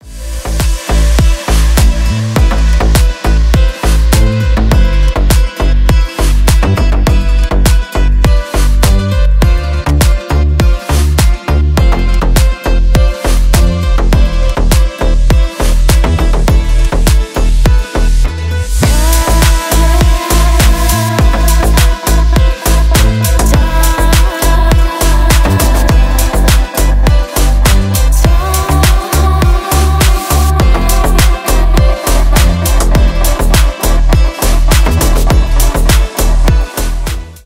• Качество: 320 kbps, Stereo
Танцевальные
клубные
грустные